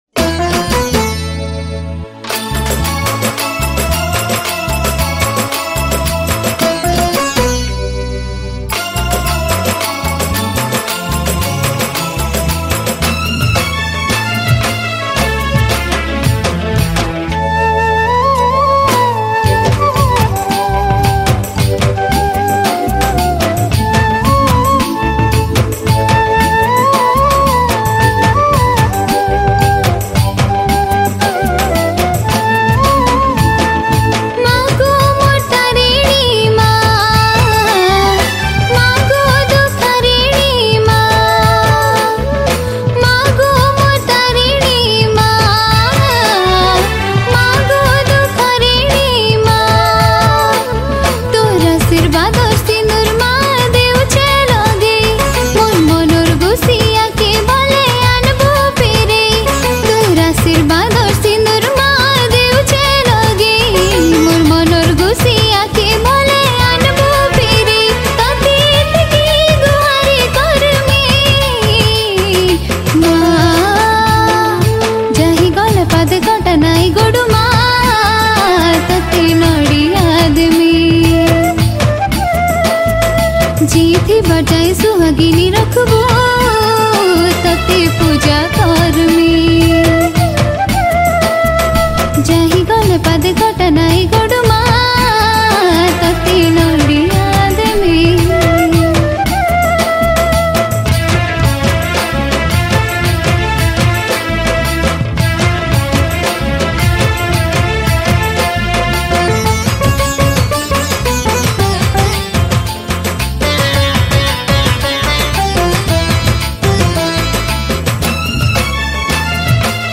Category: Sambalpuri Bhakti Songs 2022